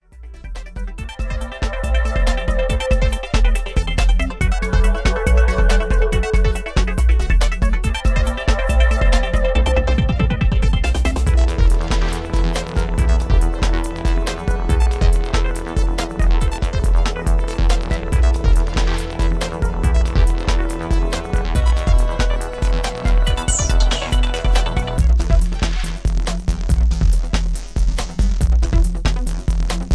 Intense Electro Ambient with feel of tension